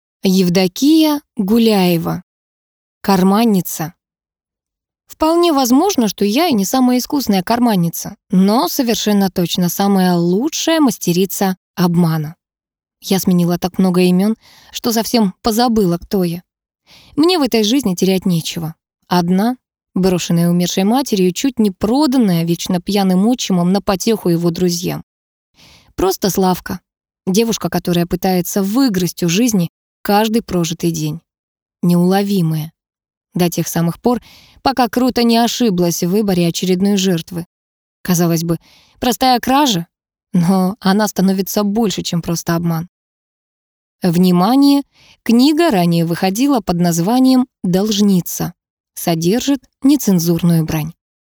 Аудиокнига Карманница | Библиотека аудиокниг